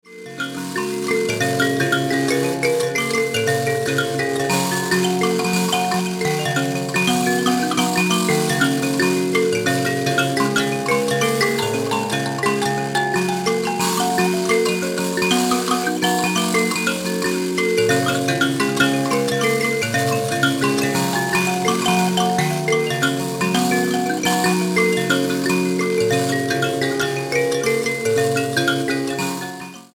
in mavembe tuning